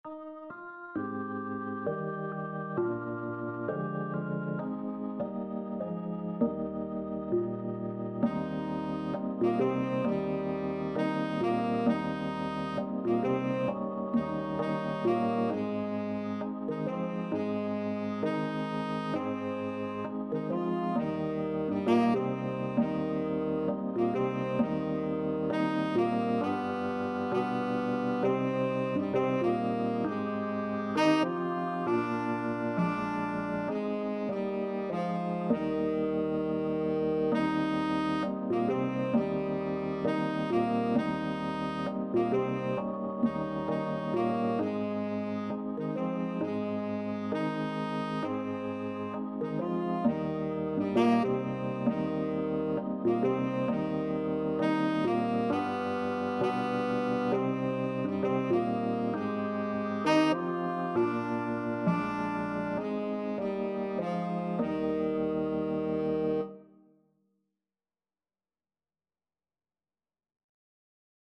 Pop (View more Pop Alto-Tenor-Sax Duet Music)